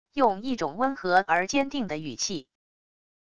用一种温和而坚定的语气wav音频